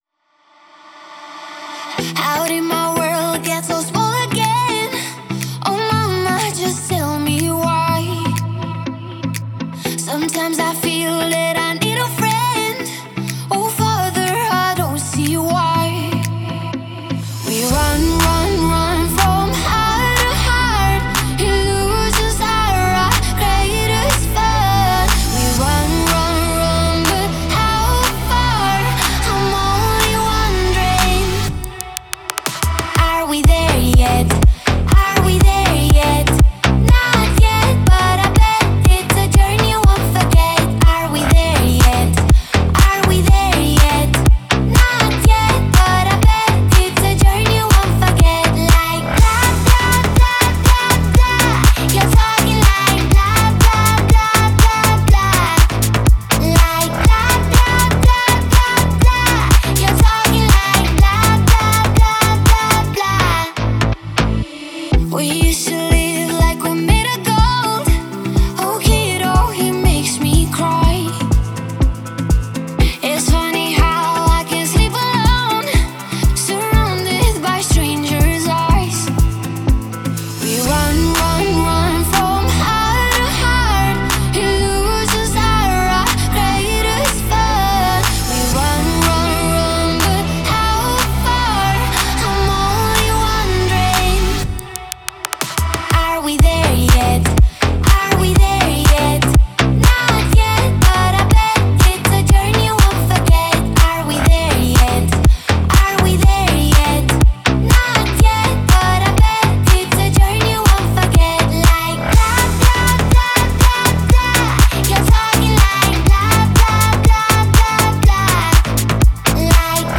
это яркий трек в жанре поп с элементами электронной музыки